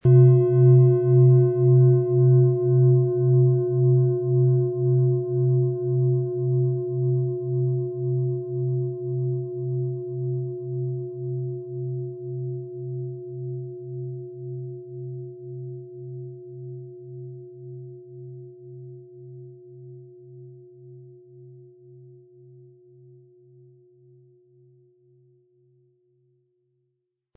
• Mittlerer Ton: Delfin
Wir haben versucht den Ton so authentisch wie machbar hörbar zu machen, damit Sie hören können, wie die Klangschale bei Ihnen klingen wird.
PlanetentöneWasser & Delfin & Mond (Höchster Ton)
MaterialBronze